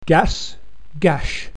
gasgash.mp3